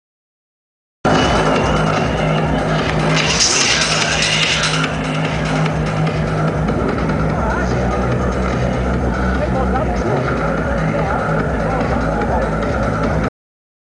The audio I pulled off the camera is purest crud.
13 seconds of hideous noise and you got it.